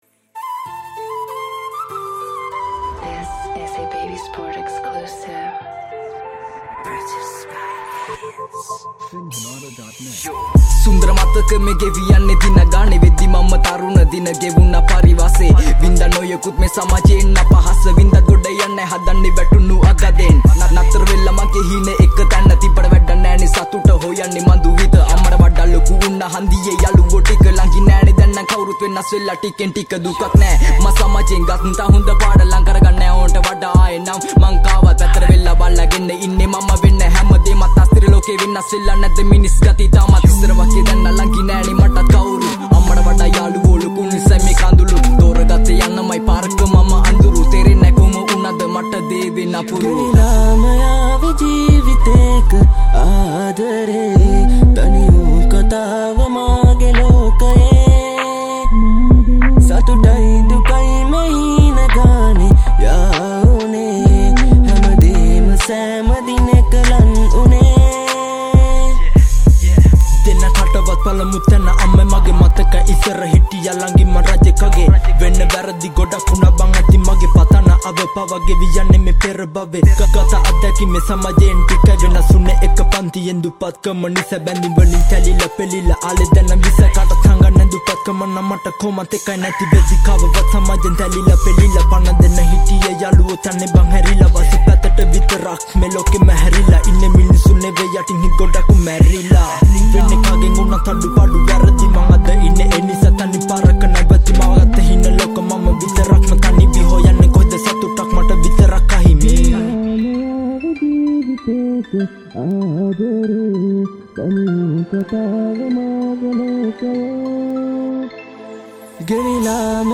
Category: Rap Songs